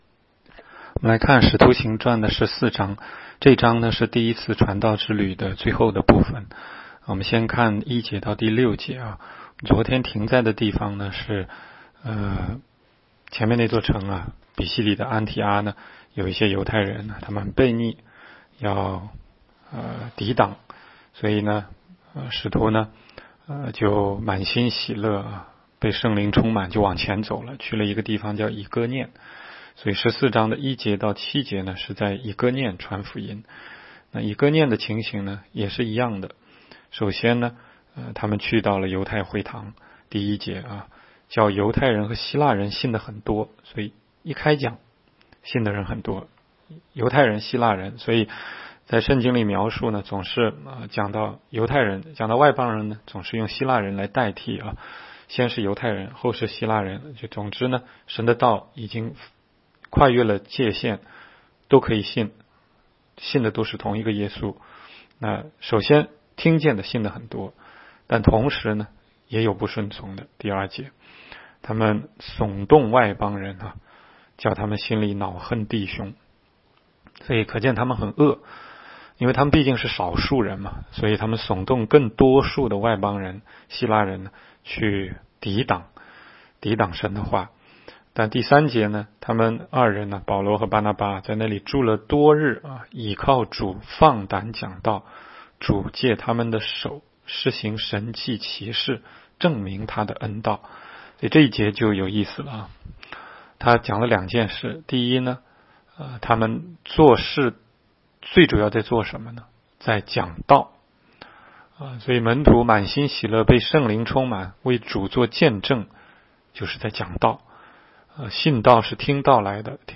16街讲道录音 - 每日读经-《使徒行传》14章